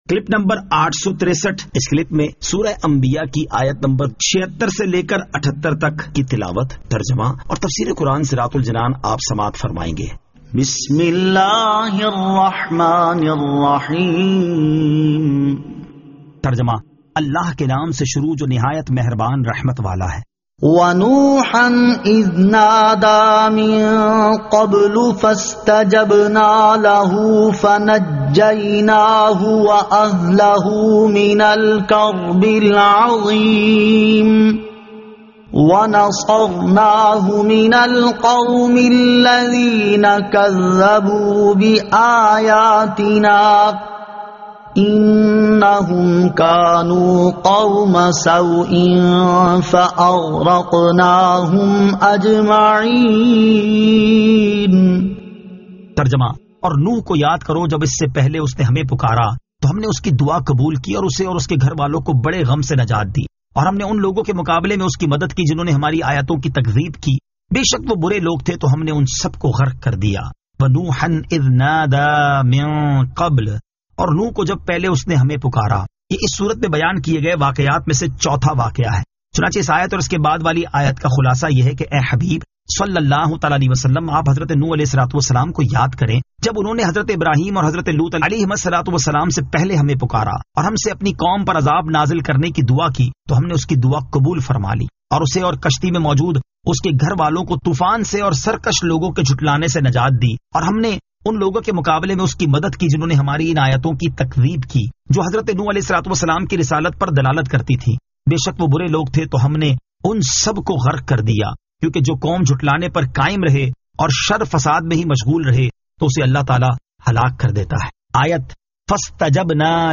Surah Al-Anbiya 76 To 78 Tilawat , Tarjama , Tafseer